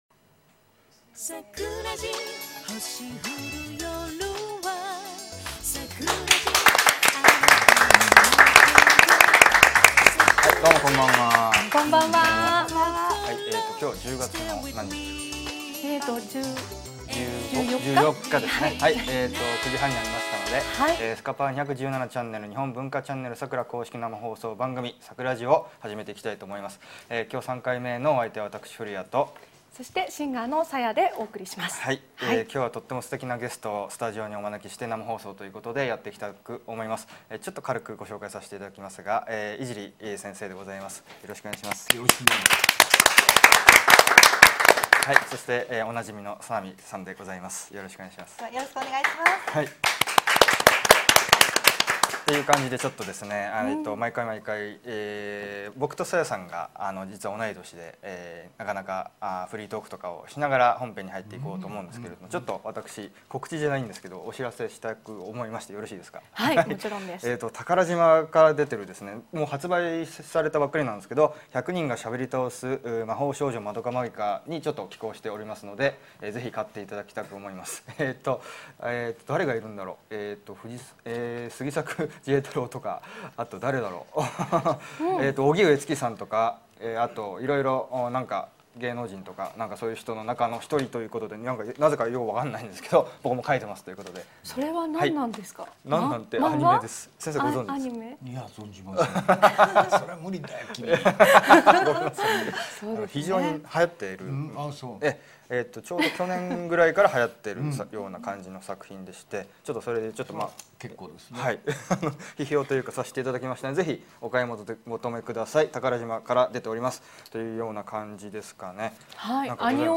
会場、ニコ生も大爆笑！！